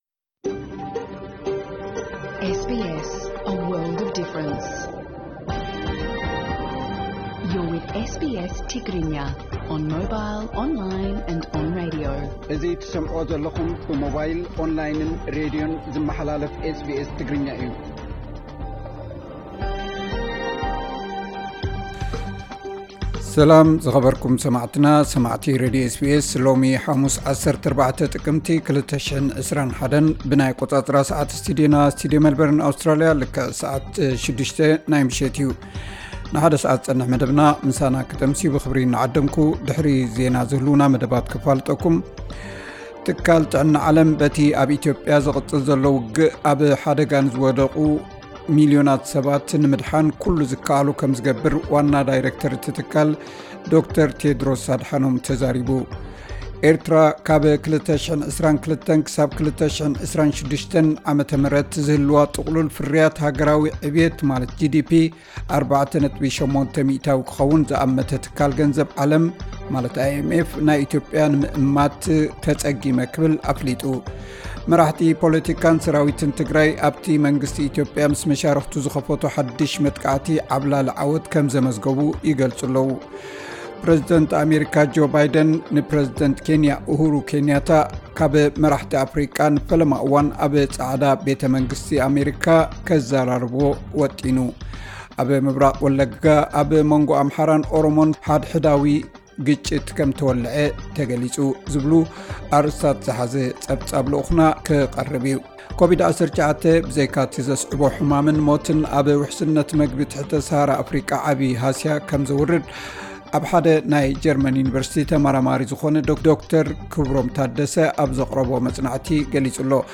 ኬንያን ሶማሊያን ዝሰሓሓባሉ ናይ ባሕር ግዝኣት እቲ ዝበዝሐ ክፋል ናይ ሶማሊያ ከምዝኾነ ኣህጉራዊ ቤት ፍርዲ ኣብ ዘሄግ ወሲኑ...(ዕለታዊ ዜና)